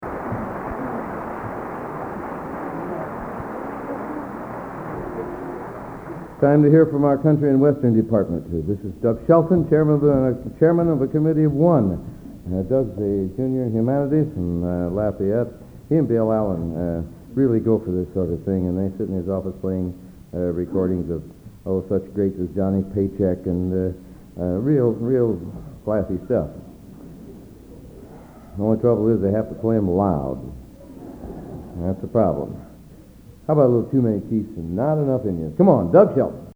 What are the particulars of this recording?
Collection: End of Season, 1979 Location: West Lafayette, Indiana